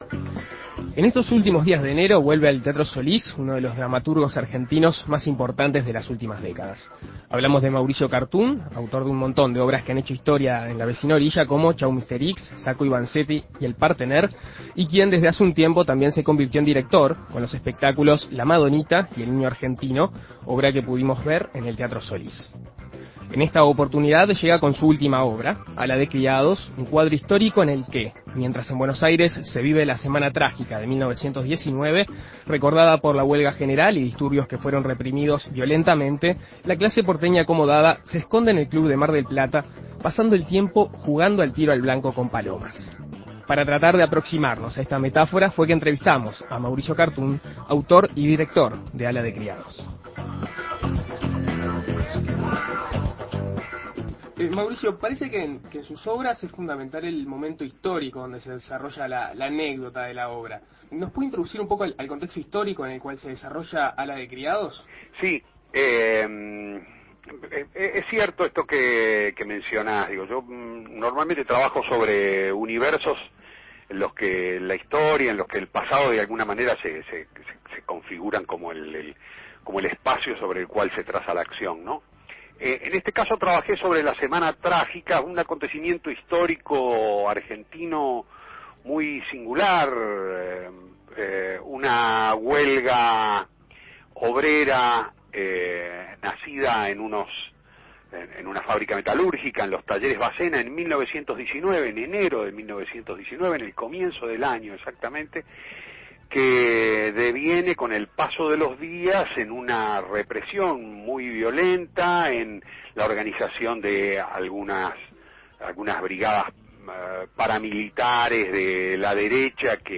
Se trata de Mauricio Kartun, autor y director de obras que han hecho historia en la vecina orilla. En esta oportunidad llega con su último trabajo, Ala de criados. Escuche la entrevista de En Perspectiva Segunda Mañana.